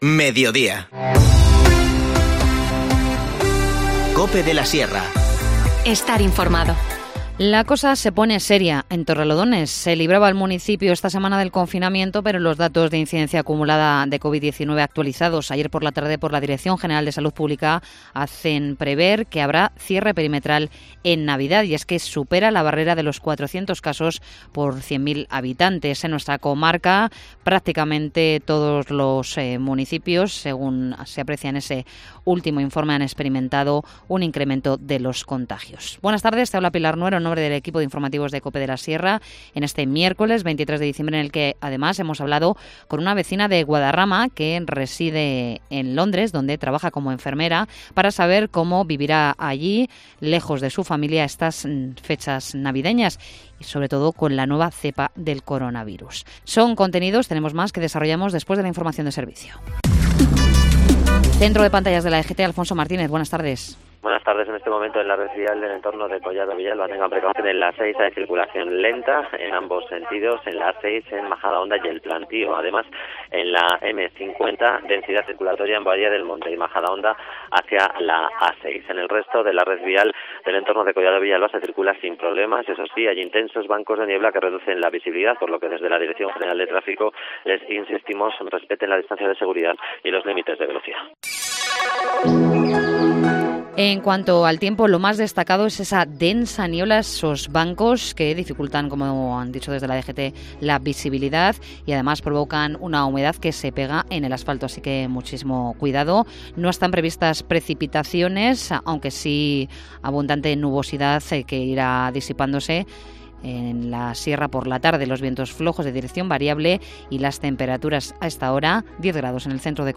- Una vecina de Guadarrama en Londres habla sobre la nueva cepa